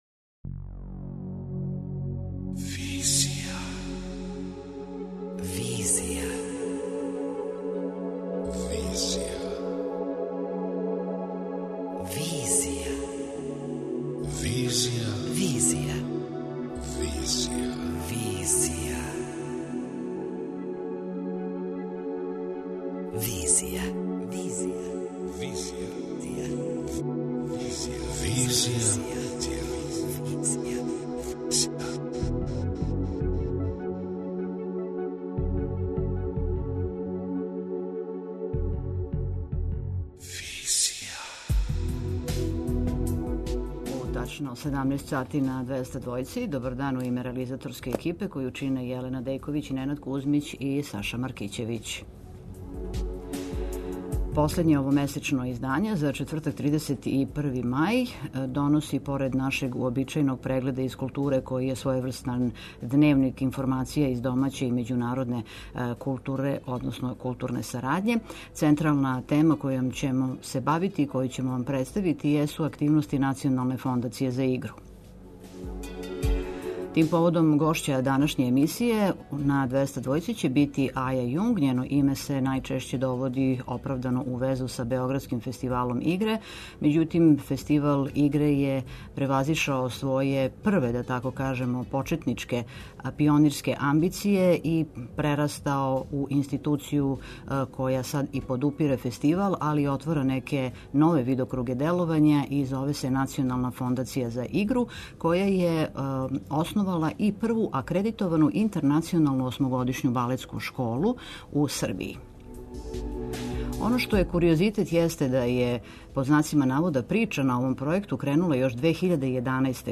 преузми : 26.63 MB Визија Autor: Београд 202 Социо-културолошки магазин, који прати савремене друштвене феномене.